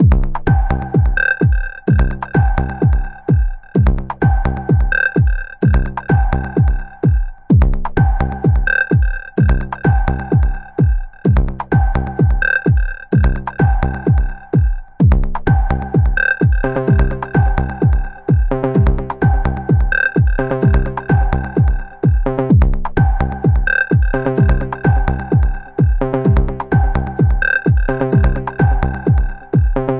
热带和弦
描述：一个E小调的热带房子类型的和弦循环。我把马林巴琴分层在合成器上，然后与合成器低音和副低音分层。这个循环有侧链。